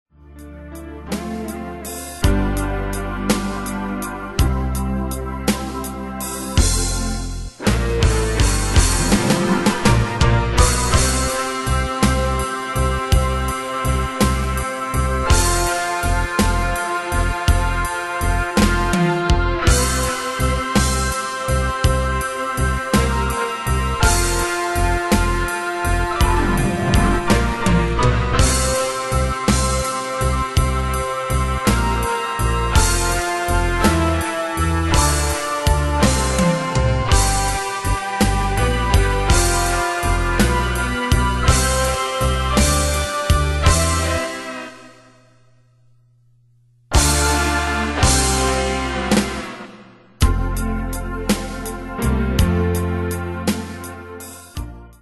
Style: PopFranco Année/Year: 1996 Tempo: 55 Durée/Time: 3.36
Danse/Dance: SlowRock Cat Id.
Pro Backing Tracks